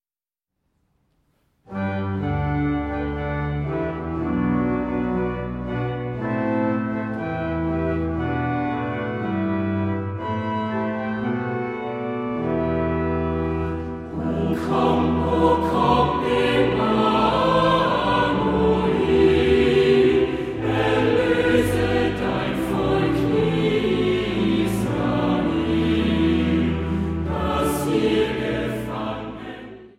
Advents- und Weihnachtsmusik
Orgel
eingespielt in der Michaeliskirche Leipzig